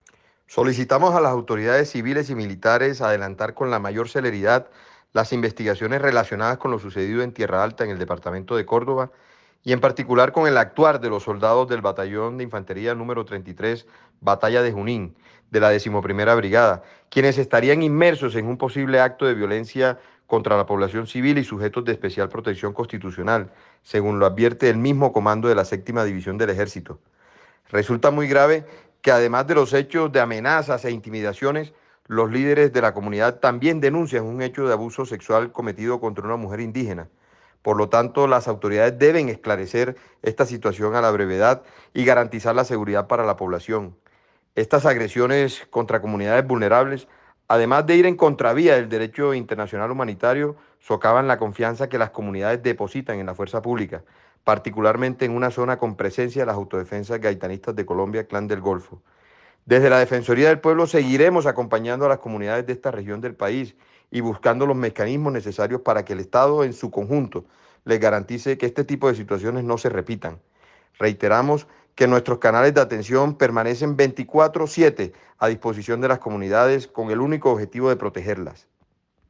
(Escuche declaraciones del Defensor del Pueblo, Carlos Camargo).